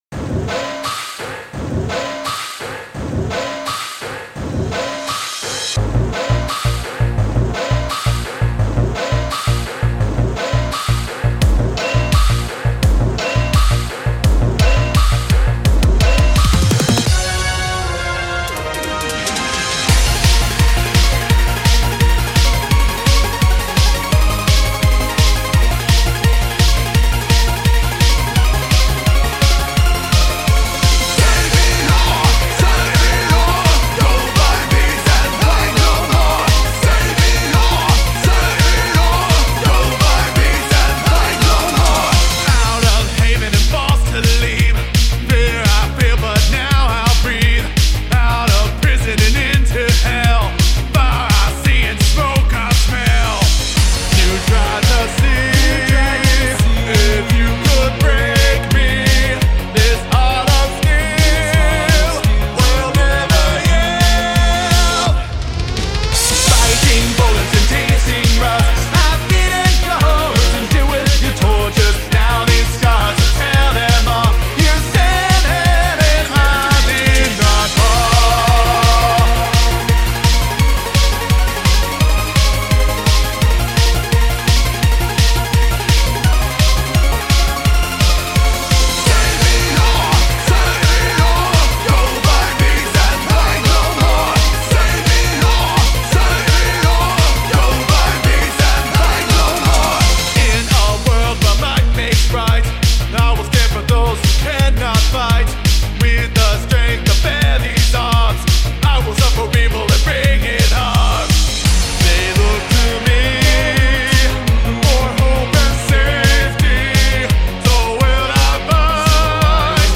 Lead Vocals
Back Vocals
Rhythm guitars
Lead Guitar